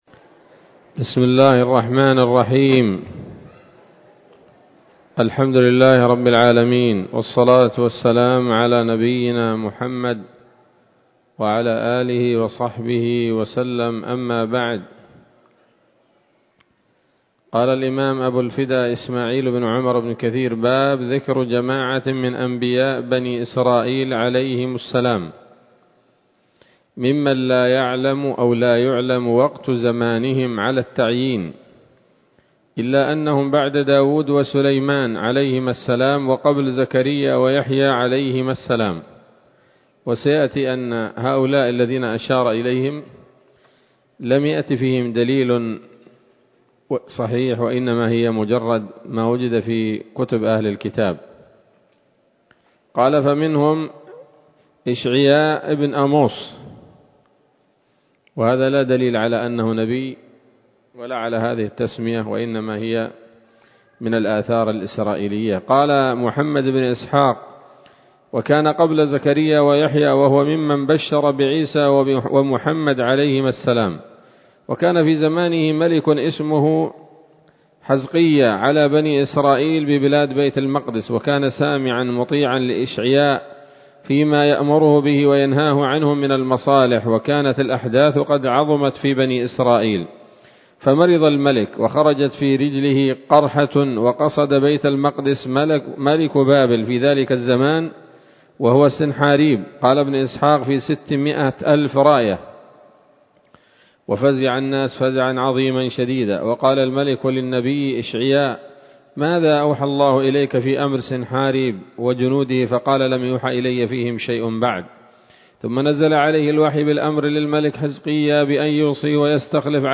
‌‌الدرس السابع والعشرون بعد المائة من قصص الأنبياء لابن كثير رحمه الله تعالى